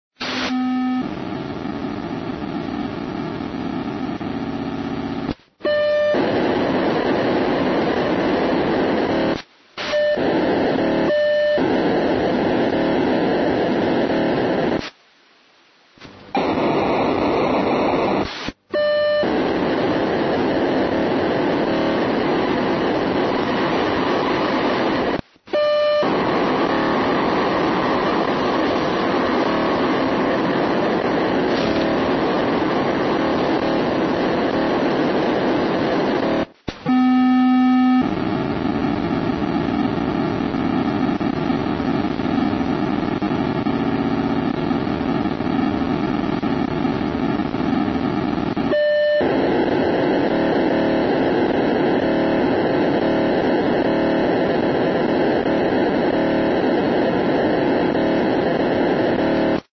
неизвестный (мне) сигнал на частоте ориентировочно 161.1мгц в Днепропетровске